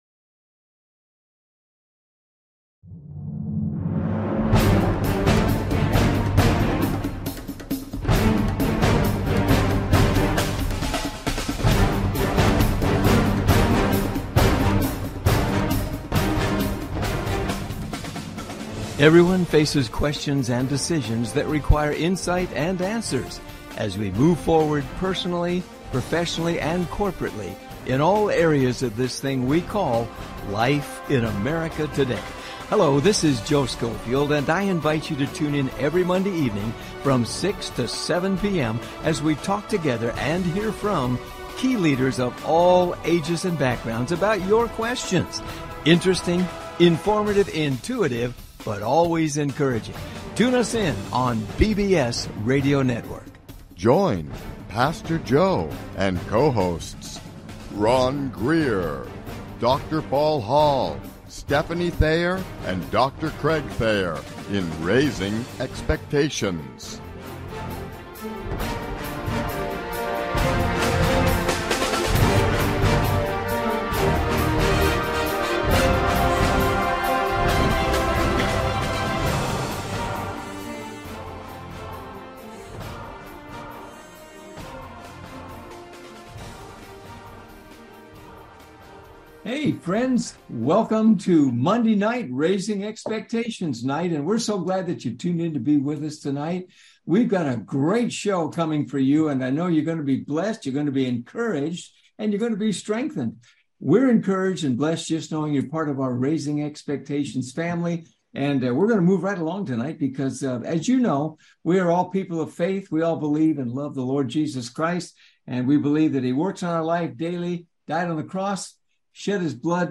Raising Expectations Talk Show